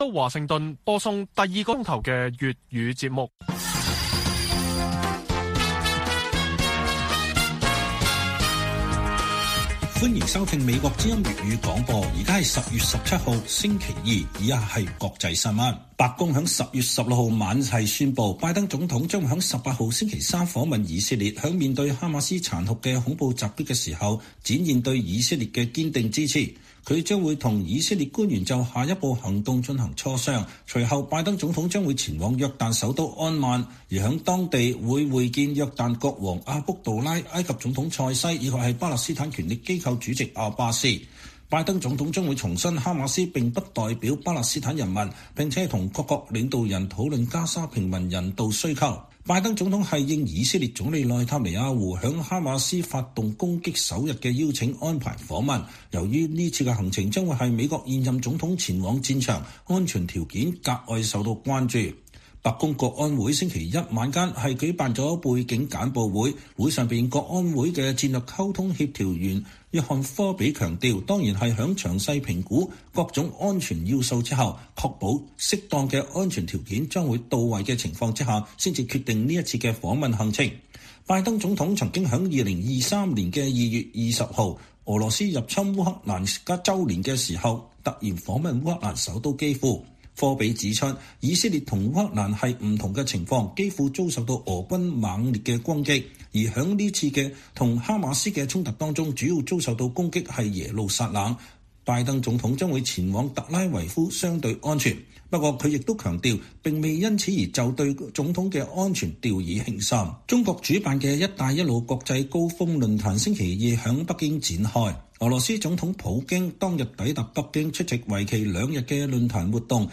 粵語新聞 晚上10-11點 : 香港兩民主黨派參選區議會，親北京媒體：痛改前非才有出路